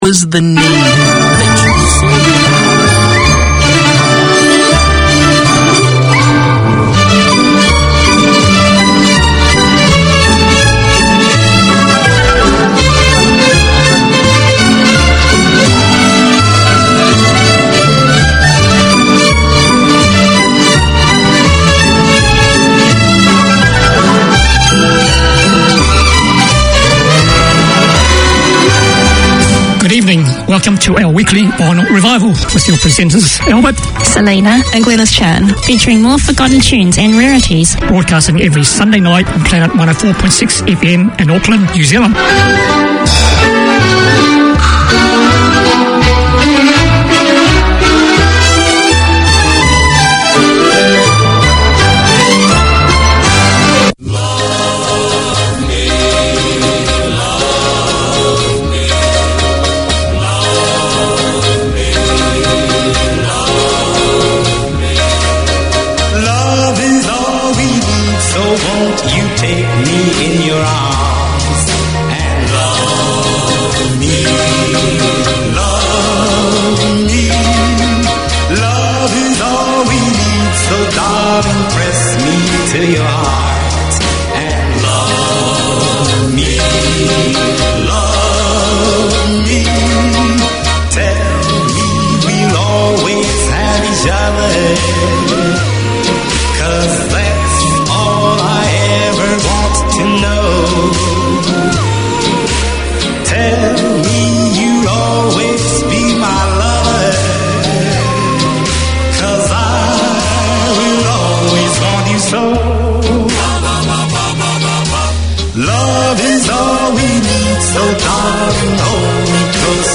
Radio made by over 100 Aucklanders addressing the diverse cultures and interests in 35 languages.
Listen for the best of the 30's,40's 50's,60's including hits and rarities.